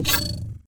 Select Robot 2.wav